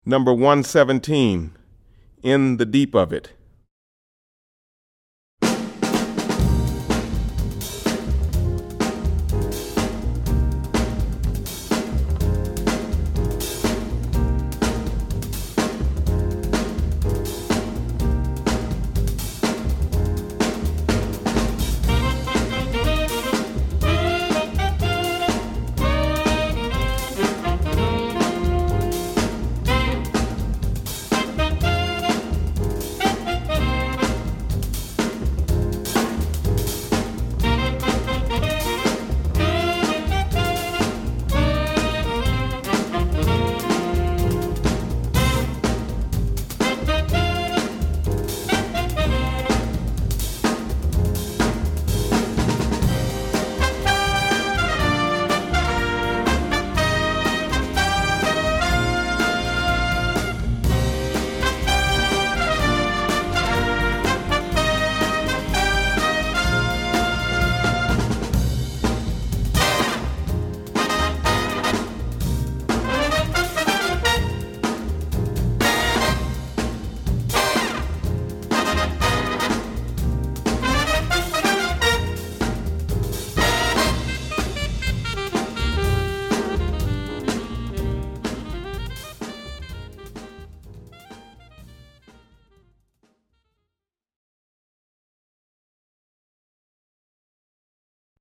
• 5 Saxophones
• 4 Trumpets
• 4 Trombones
• Vibraphone
• Guitar
• Piano
• Bass
• Drums